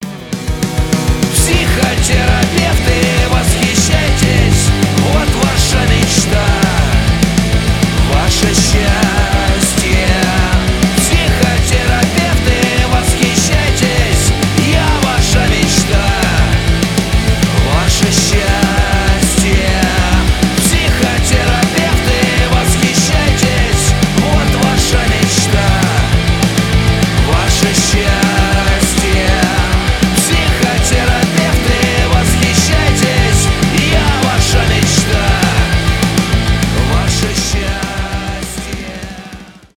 панк-рок